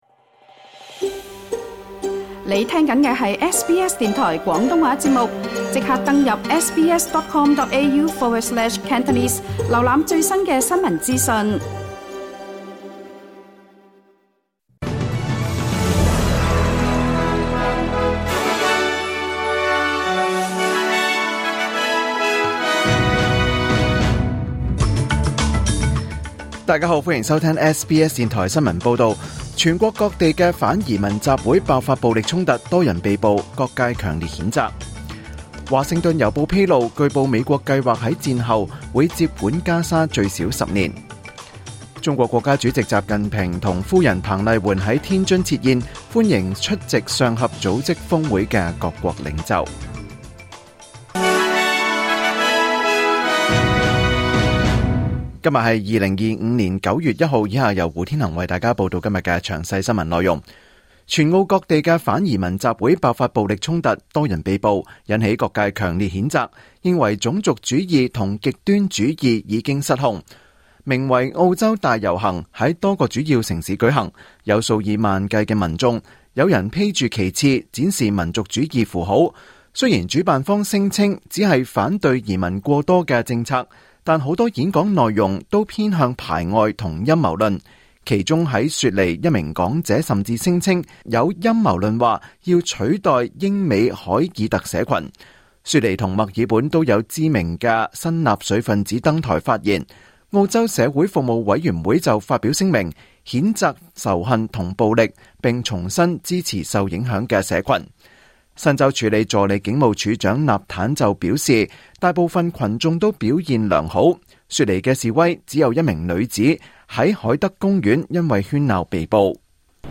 2025 年 9 月 1 日 SBS 廣東話節目詳盡早晨新聞報道。